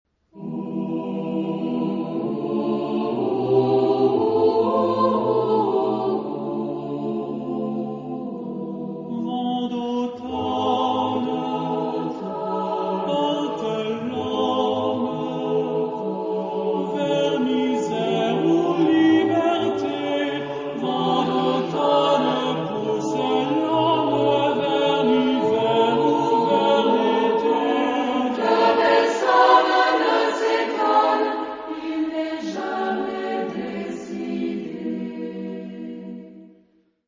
Genre-Style-Form: Secular ; Poem
Mood of the piece: poetic
Type of Choir: SATB  (4 mixed voices )
Soloist(s): Ténor (1)  (1 soloist(s))
Tonality: A minor